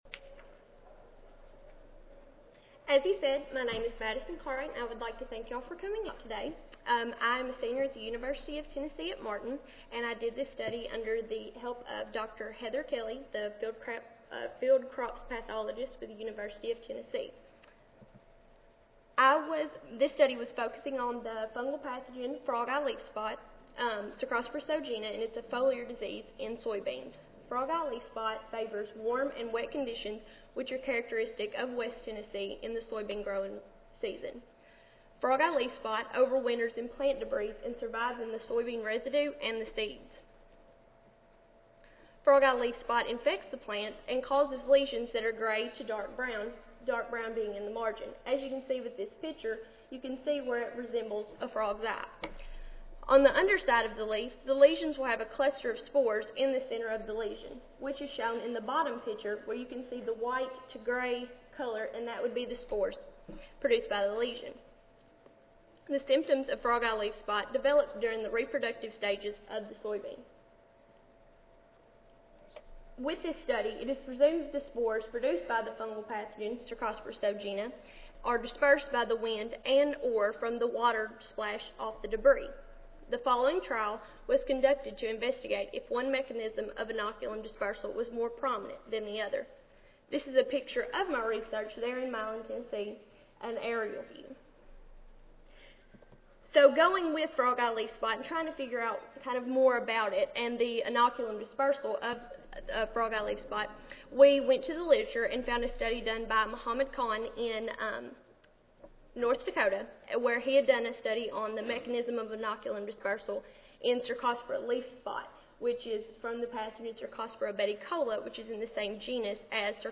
Students of Agronomy, Soils and Environmental Sciences (SASES) Session: Undergraduate Research Symposium Contest - Oral (ASA, CSSA and SSSA International Annual Meetings)
Knoxville Audio File Recorded Presentation